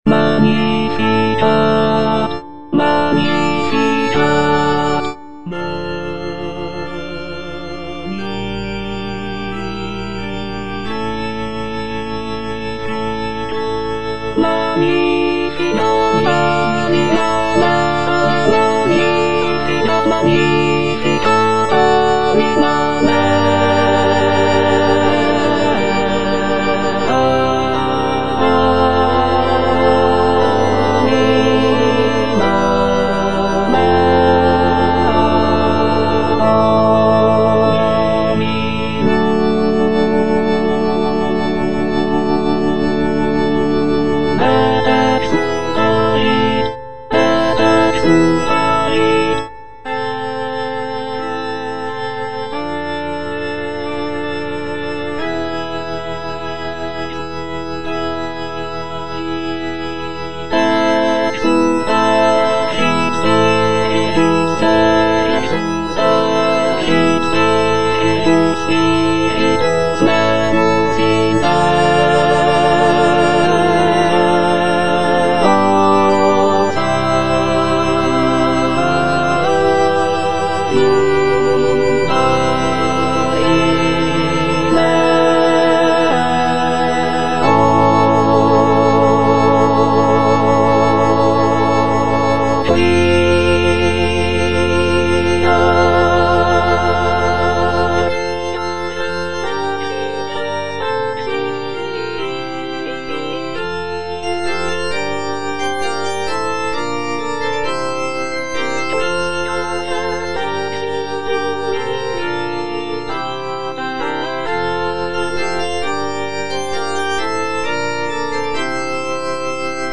C. MONTEVERDI - MAGNIFICAT PRIMO (EDITION 2) Alto I (Emphasised voice and other voices) Ads stop: